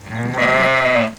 sheep.wav